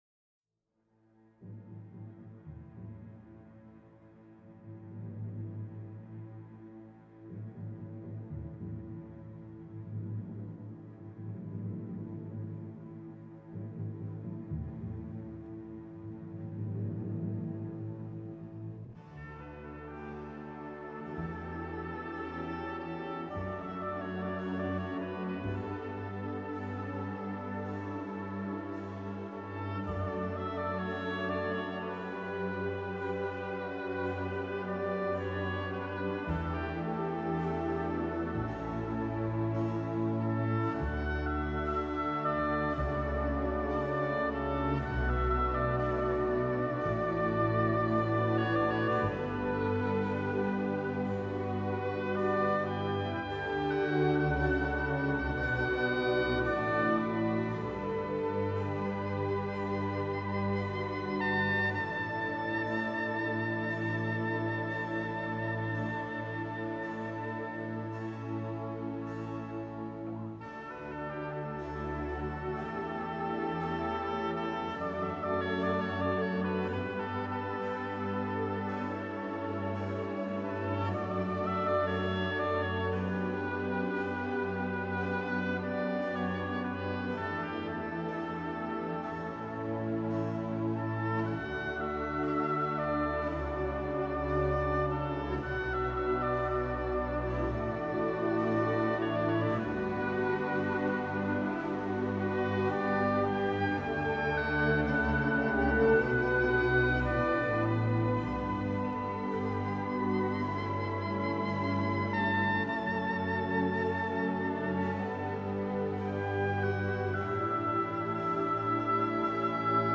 An evening event at Christ Church in Old Town, Swindon, being part of Swindon's annual Old Town Festival
The above two pictures courtesy of my camcorder which captured the event also.